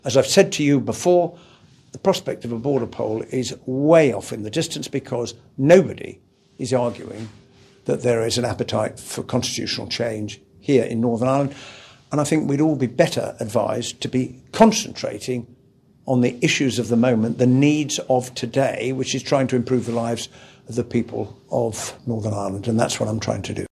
Hilary Benn made the comments during a visit to Lisburn to see the Windsor Framework in action.
When asked by reporters about the possibility of a border poll, Mr. Benn said there were far more pressing issues………….